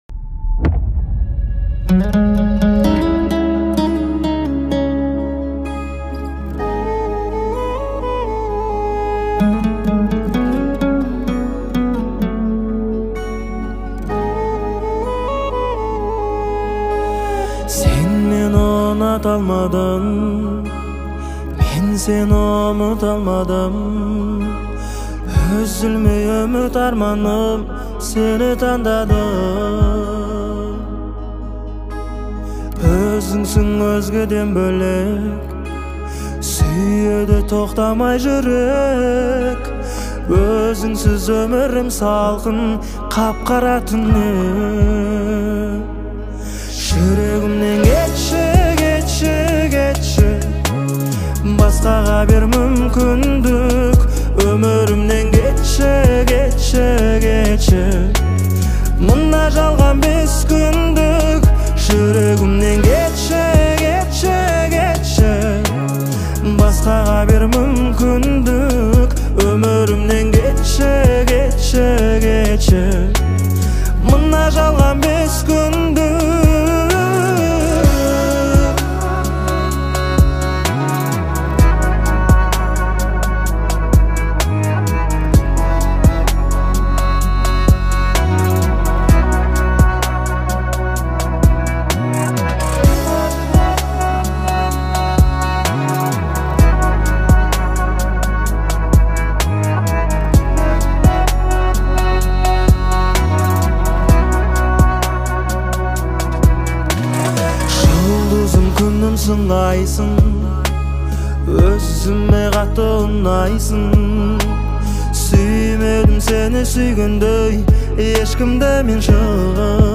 • Категория: Казахские песни /